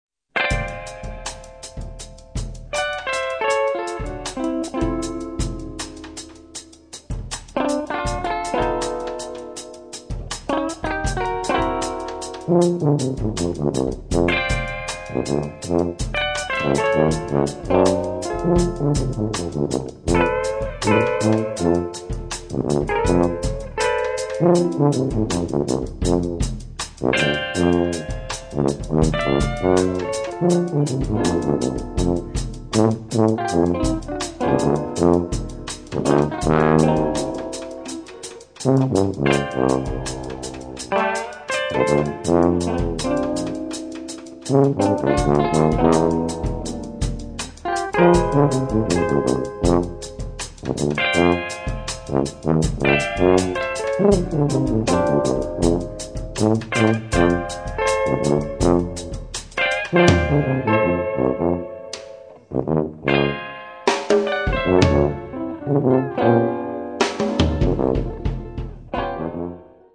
guitar, electronics
tuba
drums, percussion